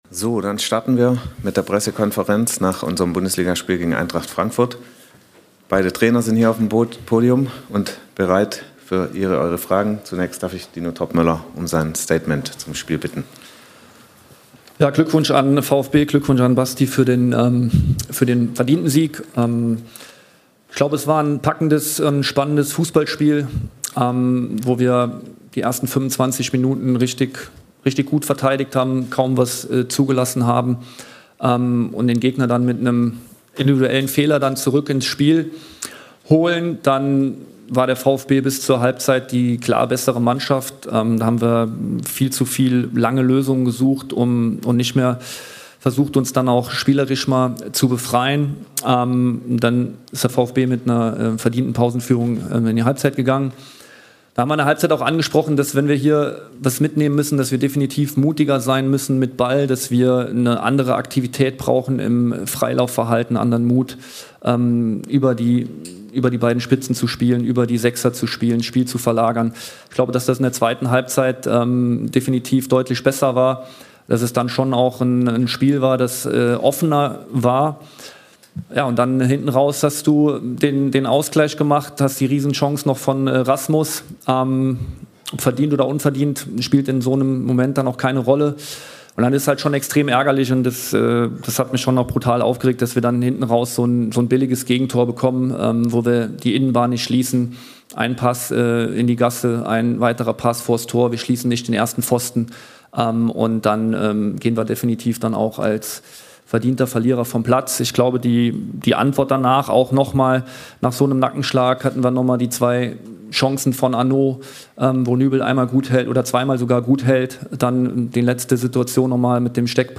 "Extrem ärgerlich" I Pressekonferenz nach VfB Stuttgart - Eintracht Frankfurt ~ Eintracht Aktuell Podcast
Die beiden Cheftrainer Sebastian Hoeneß und Dino Toppmöller sprechen über die 2:3-Auswärtsniederlage der SGE beim VfB Stuttgart.